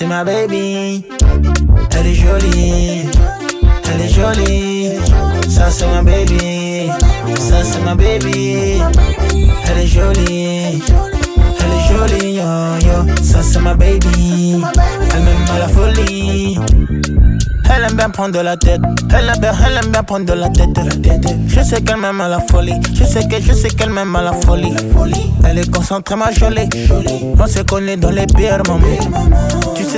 Hip-Hop Rap
Жанр: Хип-Хоп / Рэп